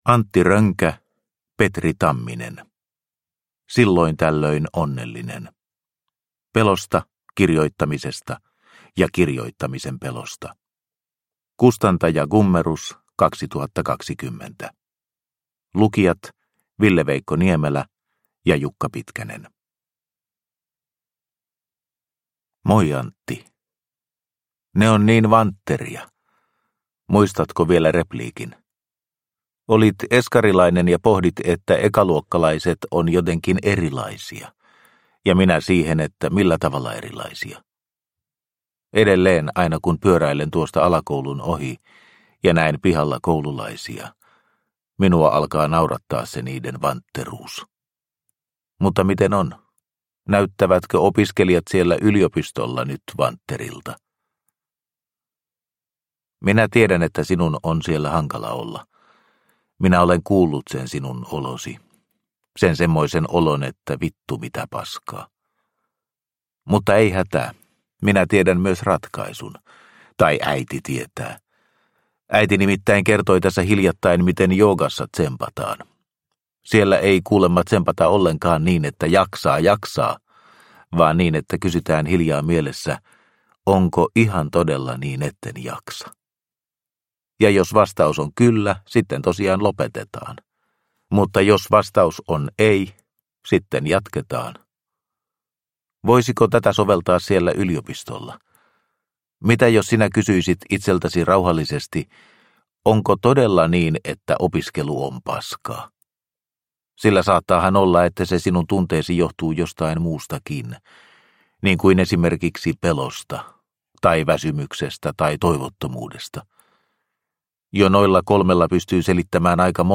Silloin tällöin onnellinen – Ljudbok – Laddas ner